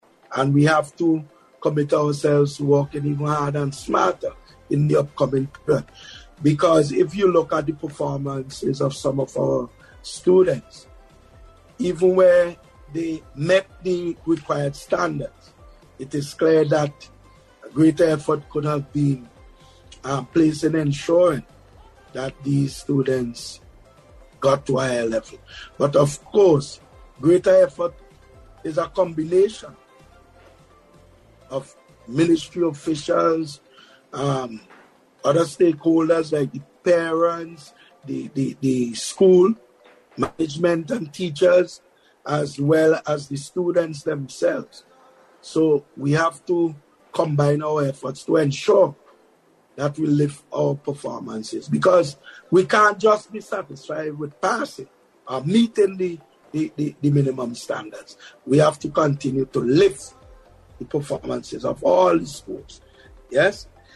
In an interview with the Agency for Public Information, the Minister said although he is pleased with the results of the CPEA, there is room for improvement.